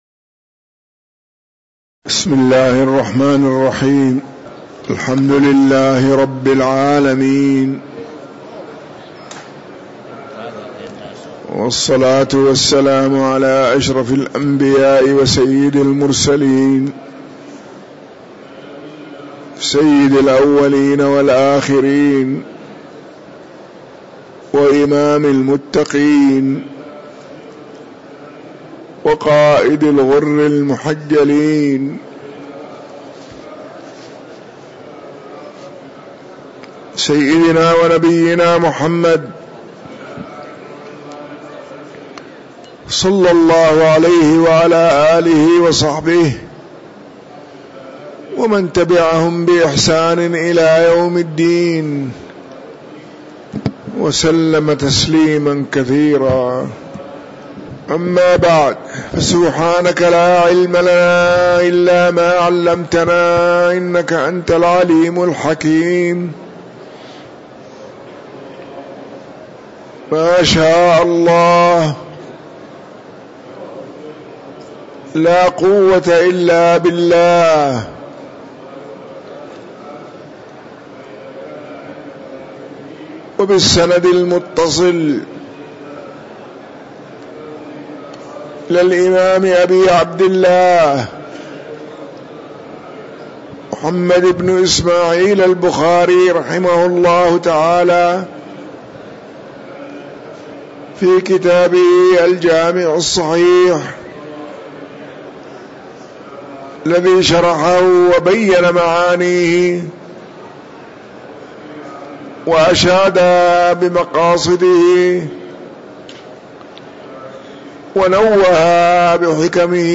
تاريخ النشر ١٩ رمضان ١٤٤٥ هـ المكان: المسجد النبوي الشيخ